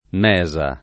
Mesa [ m $@ a ]